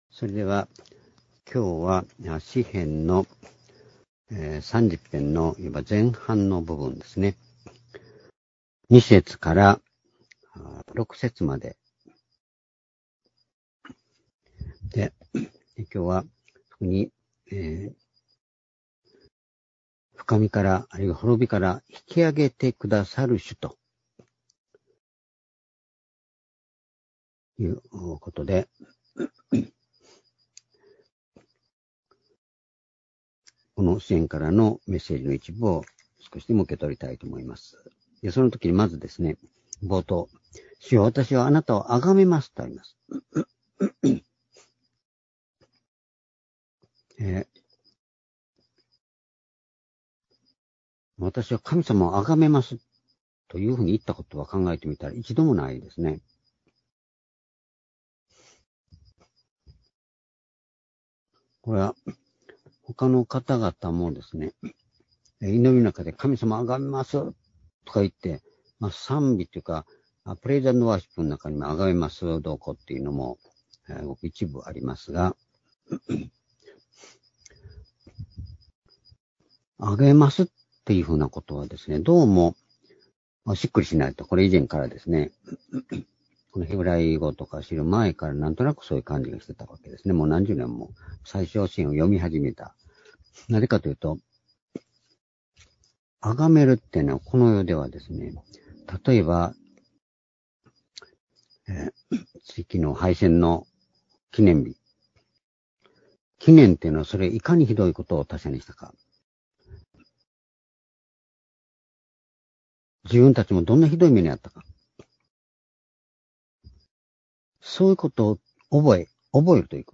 「深みから引き上げてくださる主」詩編３０編２節～６節―２０２２年８月１６日（火）夕拝